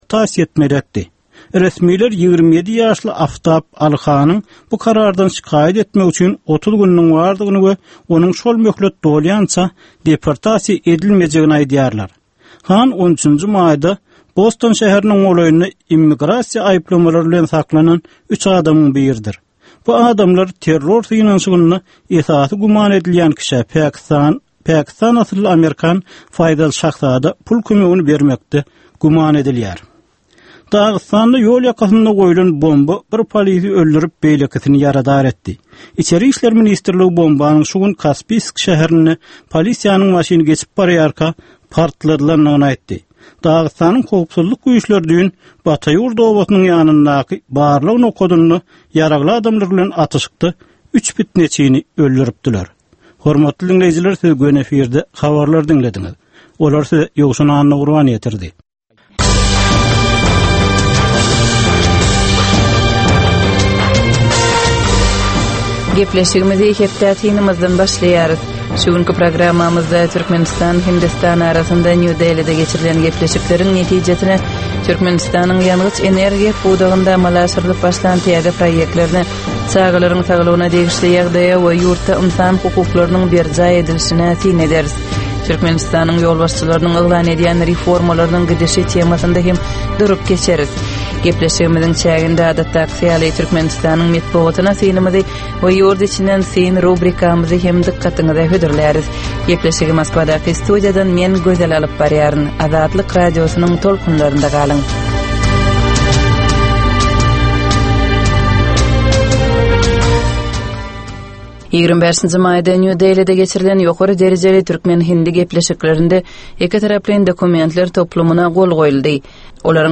Tutuş geçen bir hepdäniň dowamynda Türkmenistanda we halkara arenasynda bolup geçen möhüm wakalara syn. 25 minutlyk bu ýörite programmanyň dowamynda hepdäniň möhüm wakalary barada gysga synlar, analizler, makalalar, reportažlar, söhbetdeşlikler we kommentariýalar berilýär.